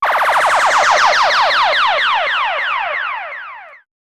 fxpTTE06006sweep.wav